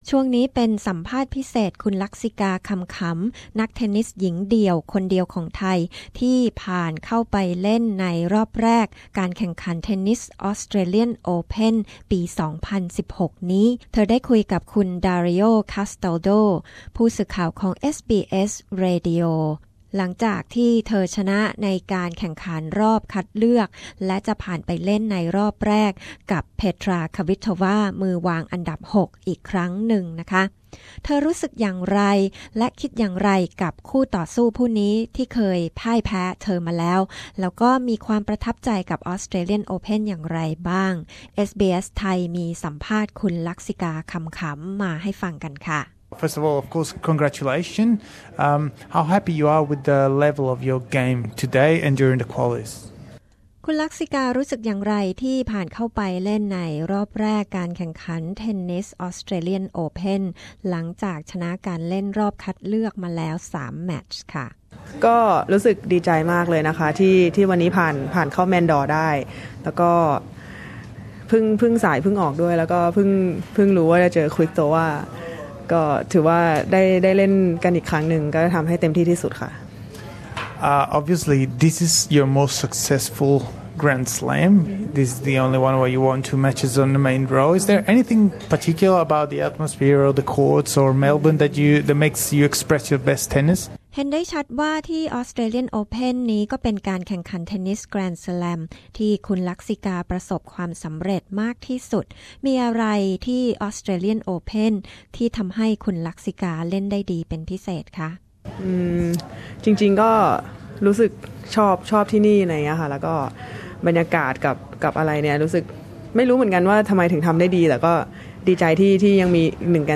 คุยกับลักษิกา คำขำ ก่อนแข่งรอบแรกศึกเทนนิส Aus Open